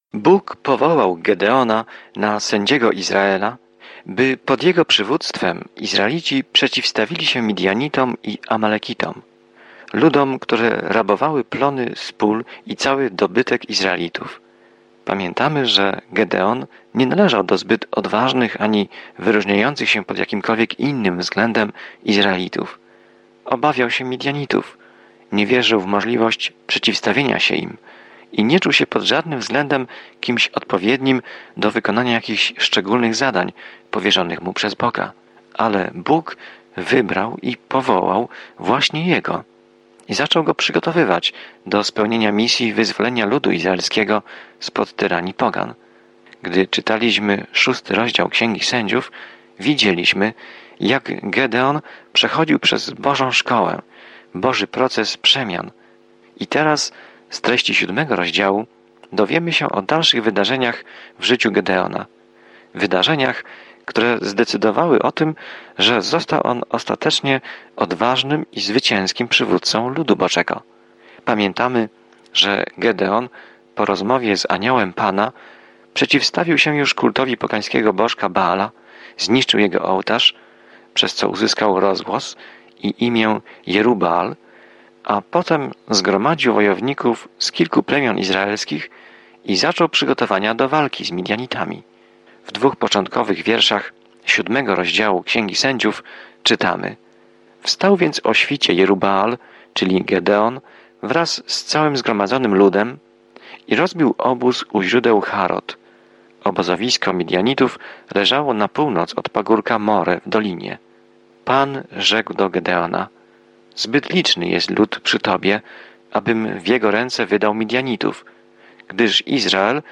Pismo Święte Sędziów 7:1-22 Dzień 6 Rozpocznij ten plan Dzień 8 O tym planie Sędziowie dokumentują czasami pokręcone i wywrócone do góry nogami życie ludzi rozpoczynających nowe życie w Izraelu. Codziennie podróżuj przez Sędziów, słuchając studium audio i czytając wybrane wersety ze słowa Bożego.